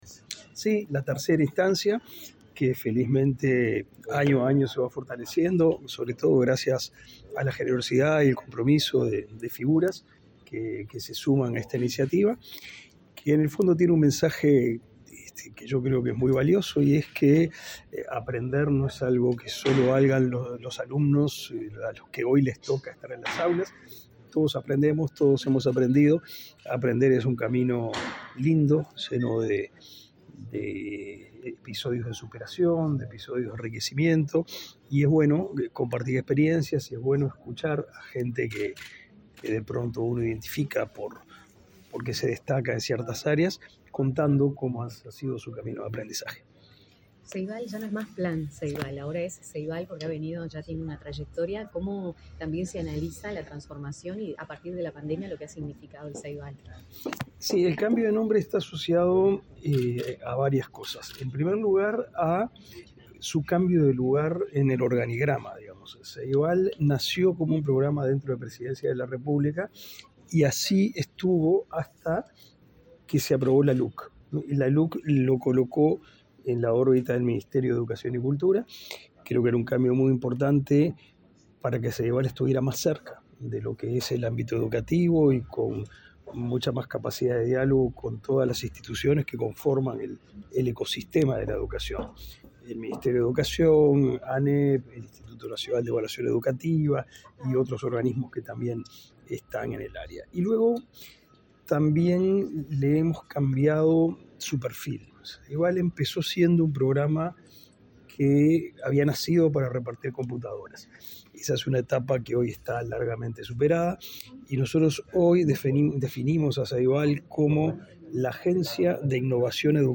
Declaraciones del ministro de Educación y Cultura, Pablo da Silveira
El ministro Pablo da Silveira participó, este miércoles 14 en el Auditorio del Sodre, del acto de lanzamiento de la tercera temporada de la serie